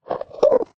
mob / endermen / idle5.ogg